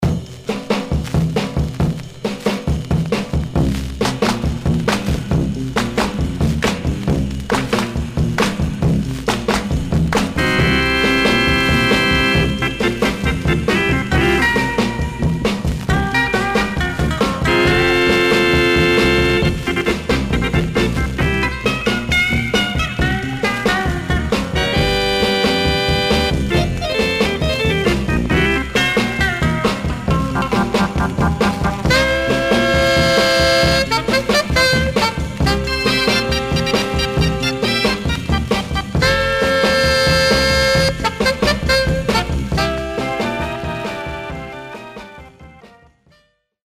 Some surface noise/wear
Mono
R & R Instrumental